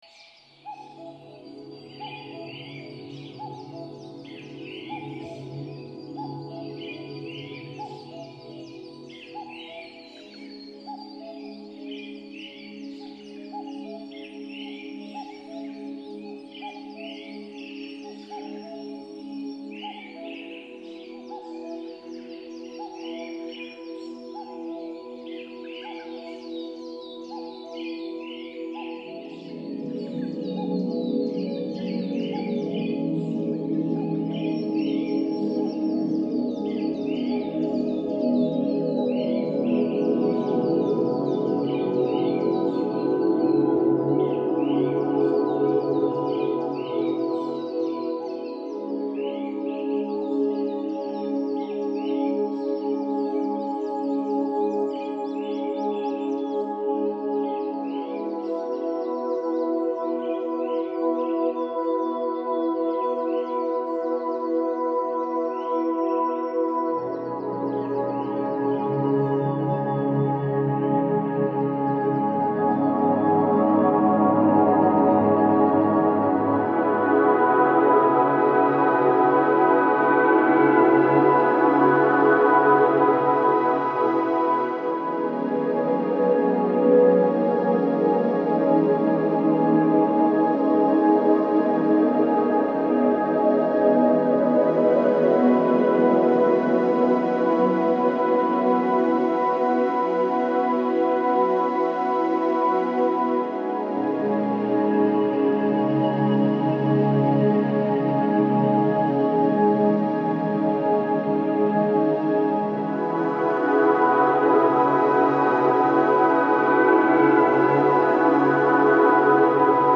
又或者别想任何困难的东西，放松，闭上眼，放平呼吸，让自己融入到这颂经声中去。